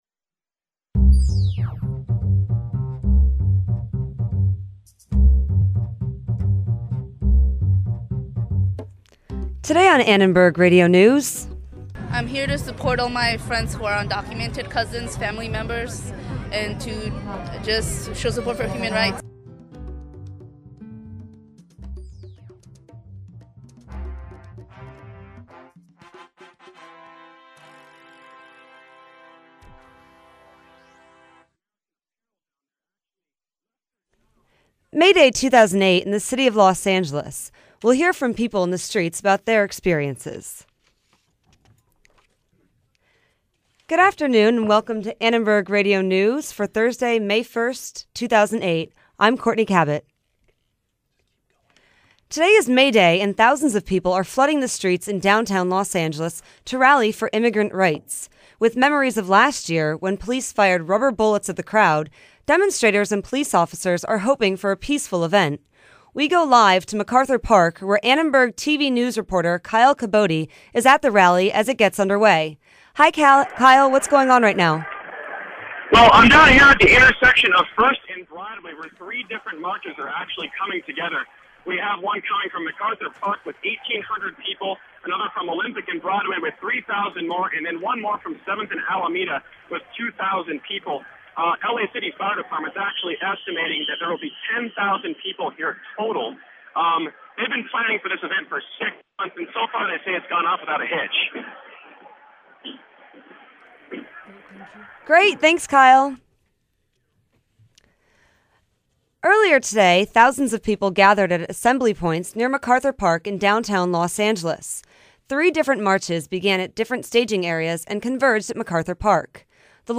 ARN's final newscast of Spring 2008 covers the Los Angeles May Day demonstrations for immigrant's rights.